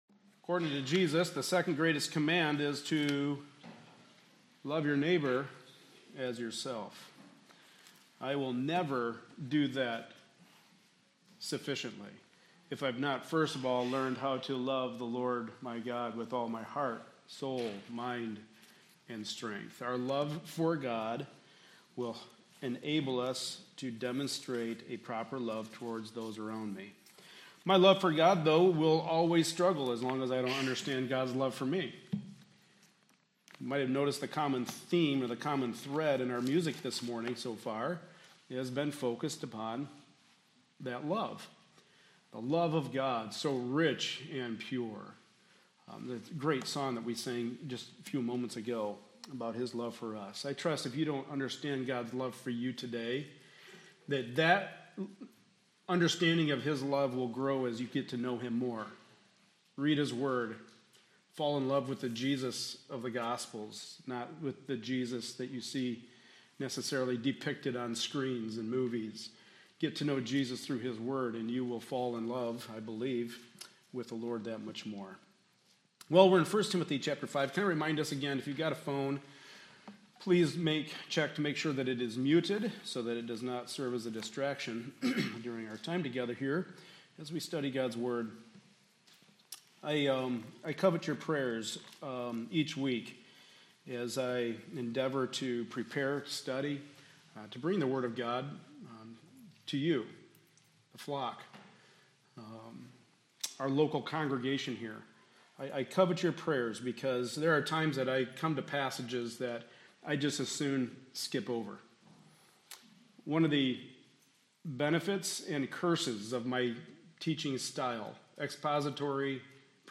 Passage: 1 Timothy 5:17-18 Service Type: Sunday Morning Service